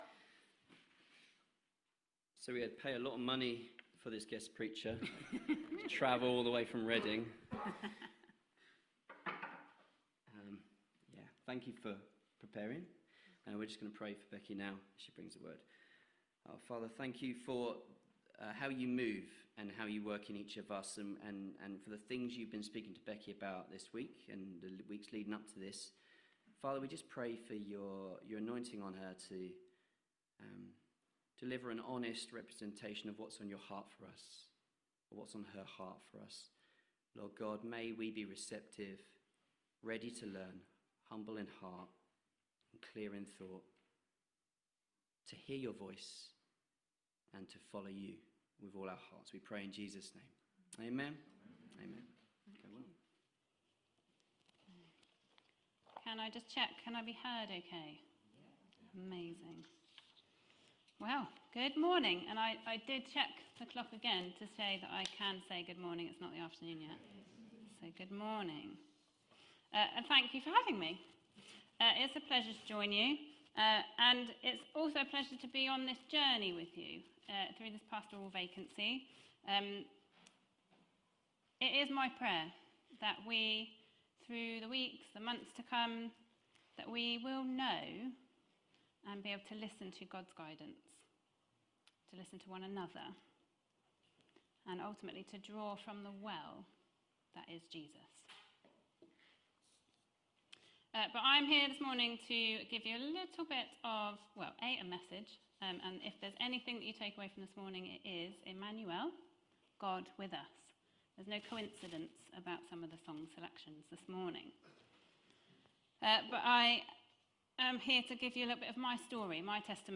Sermon 14 December 2025